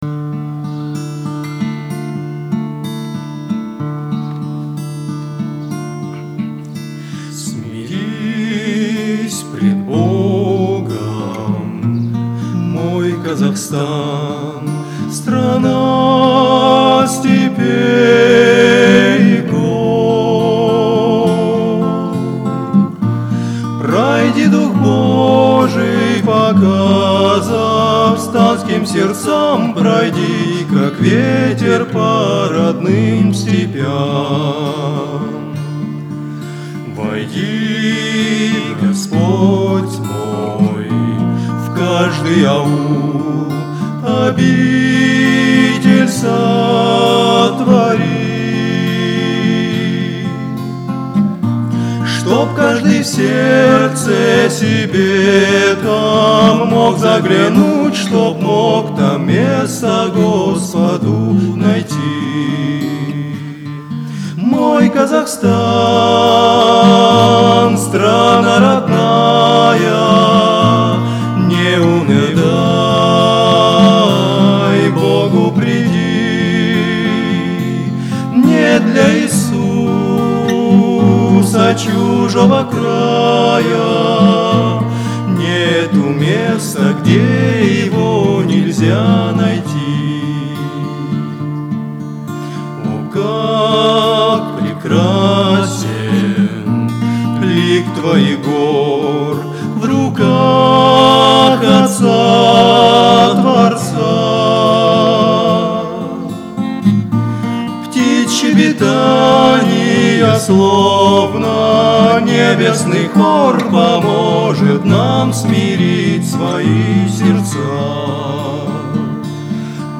on 2014-07-08 - Фестиваль христианской музыки и песни